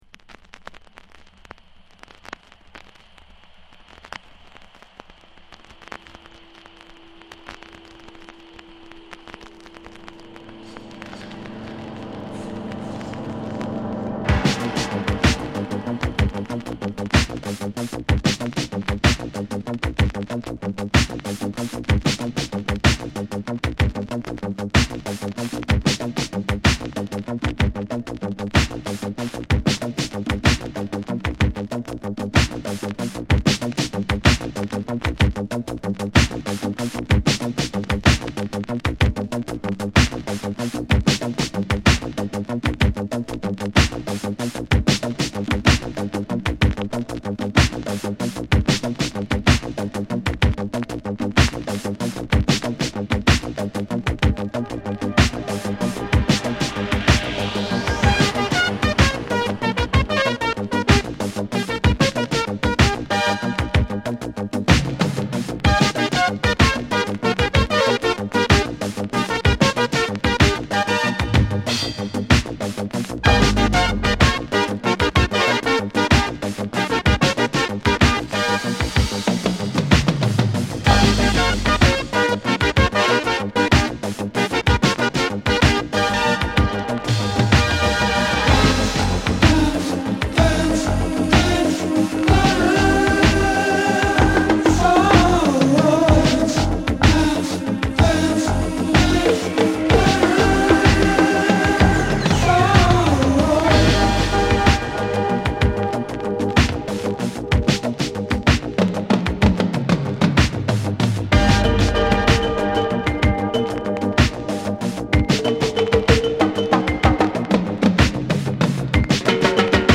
オブスキュアなフュージョン・ディスコ